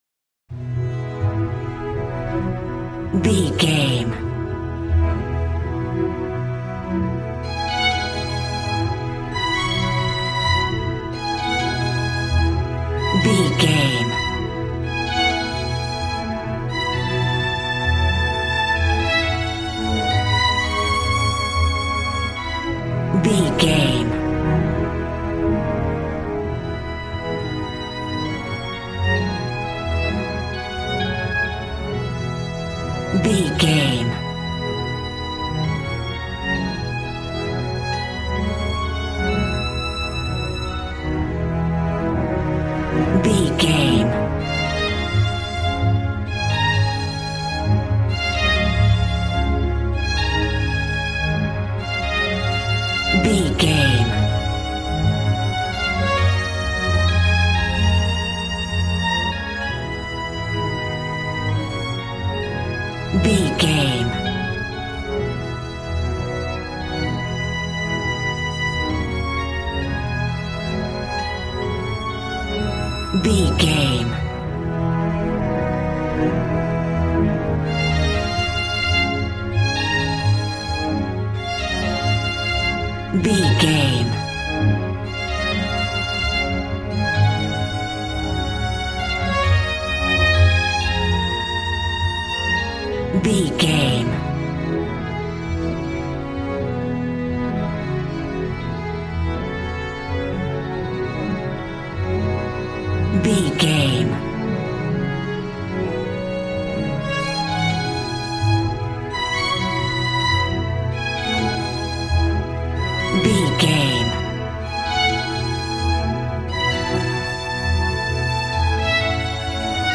Aeolian/Minor
A♭
joyful
conga
80s